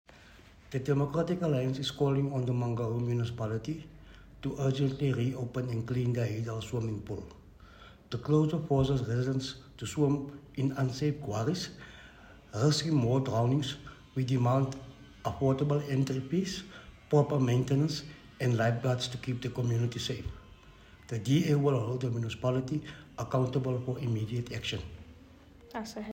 Afrikaans soundbites by Cllr Attie Terblanche and